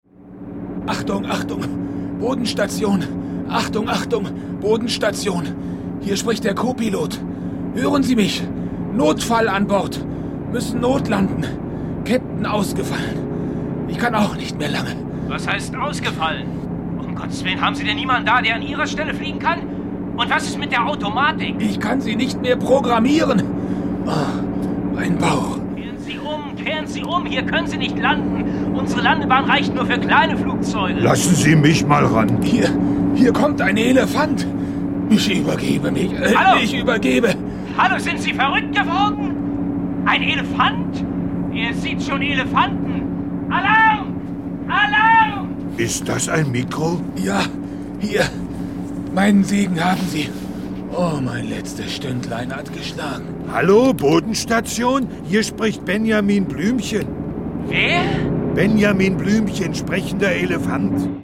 Ravensburger Benjamin Blümchen - ...als Pilot ✔ tiptoi® Hörbuch ab 3 Jahren ✔ Jetzt online herunterladen!